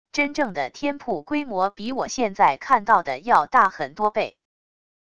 真正的天瀑规模比我现在看到的要大很多倍wav音频生成系统WAV Audio Player